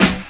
• 00's Focused Hip-Hop Acoustic Snare F# Key 68.wav
Royality free snare one shot tuned to the F# note. Loudest frequency: 2753Hz
00s-focused-hip-hop-acoustic-snare-f-sharp-key-68-rtg.wav